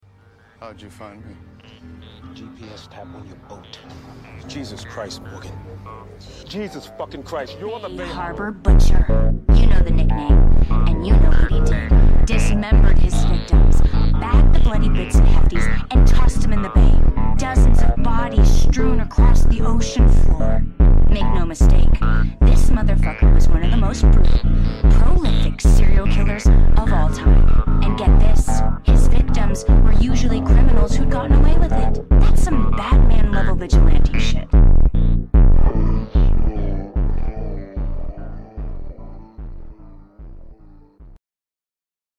instrumental slowed